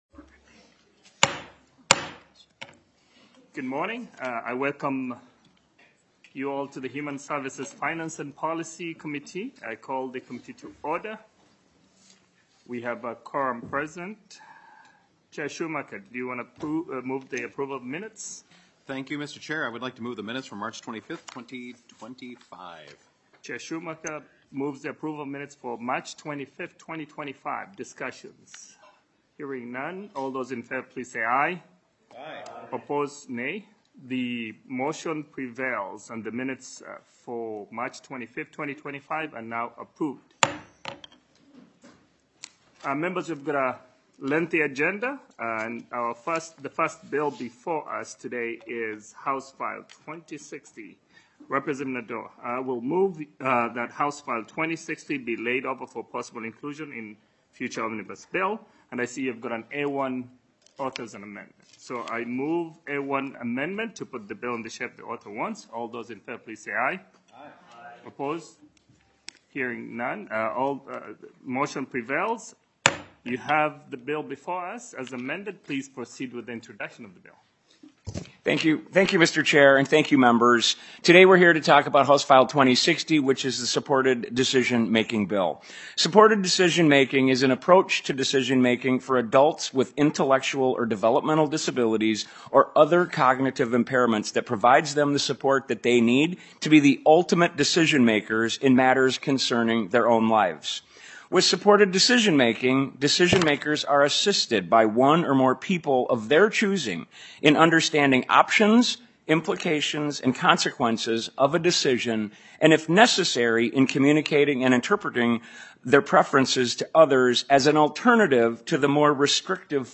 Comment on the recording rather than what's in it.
Human Services Finance and Policy TWENTIETH MEETING - Minnesota House of Representatives